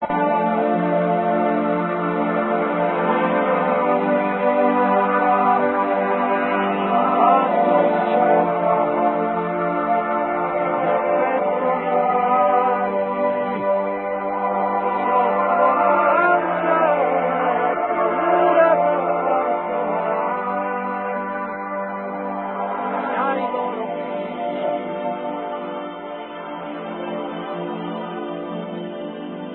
nella versione live della medesima canzone